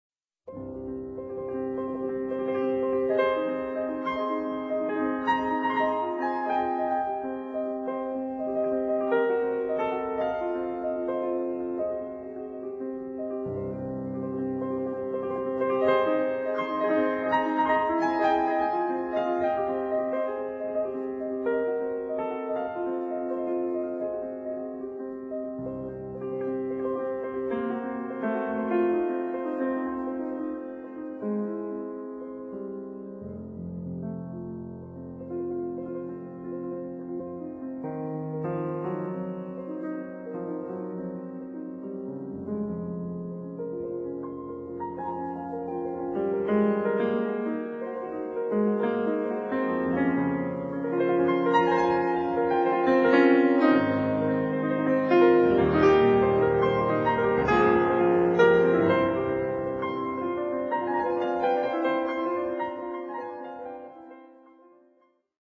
CDタイトル 商品番号 曲番 曲　名 作　曲 ピアノ